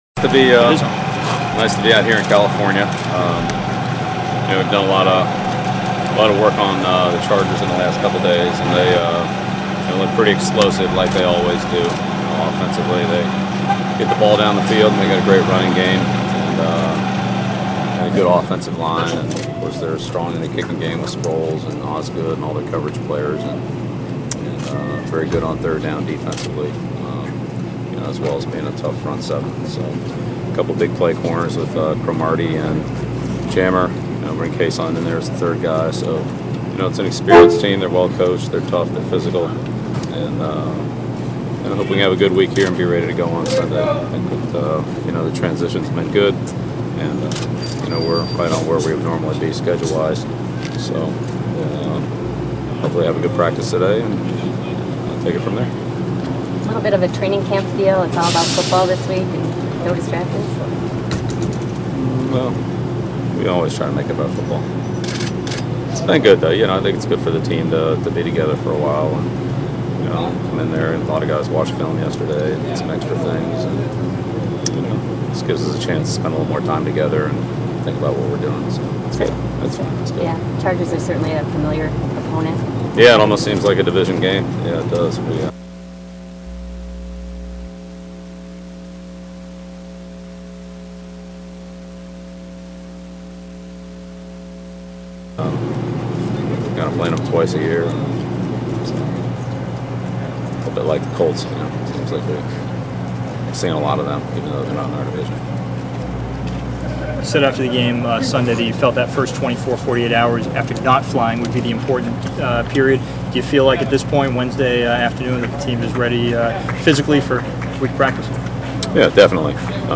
Index of /163756/prod/video/patriots/NE/videos/imported/belichick-press-conference